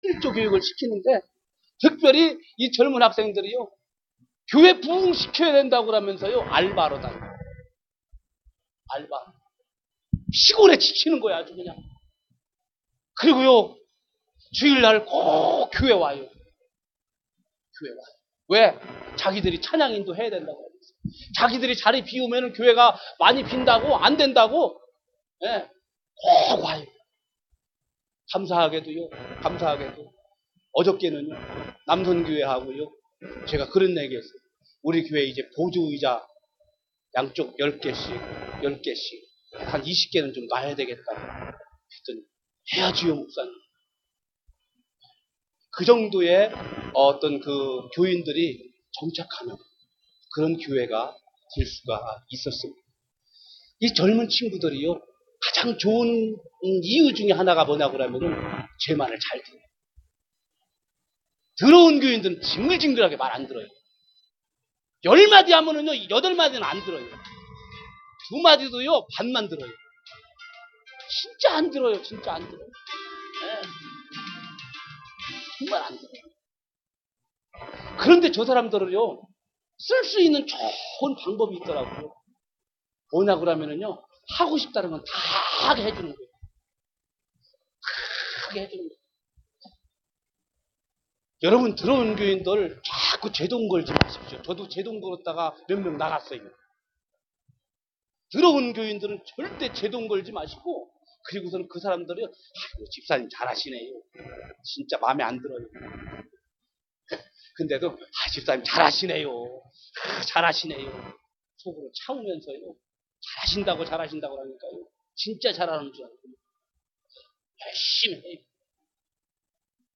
2012년 제5차 신바람목회 세미나 파일 10